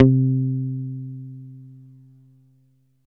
14 BASS C4.wav